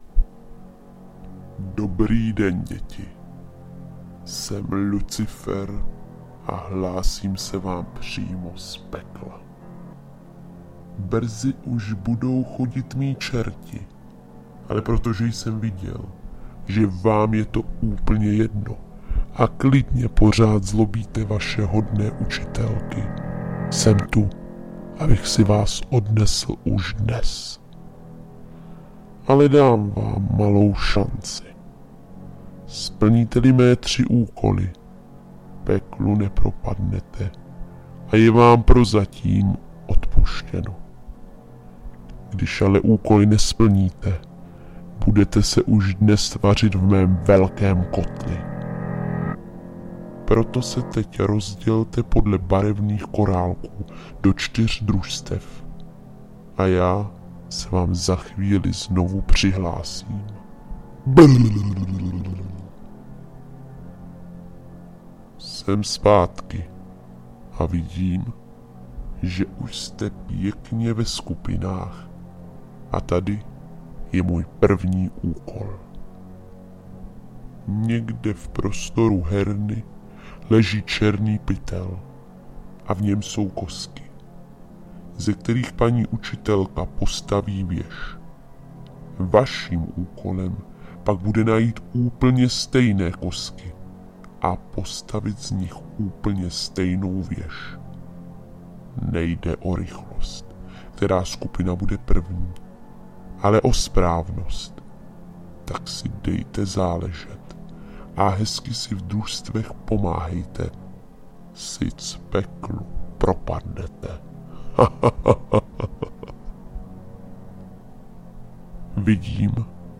S ďábelským smíchem se rozloučí.
Zodpovědně a s ohledem na děti zvažte, zda je pro ně nahrávka s hlasem Lucifera opravdu vhodná a zda dokážete děti i v takové napjaté chvíli pozitivně motivovat, povzbuzovat a i přes strašidelný hlas z nahrávky jim dodávat pocit klidu a bezpečí.
Hlas Lucifera - nahrávka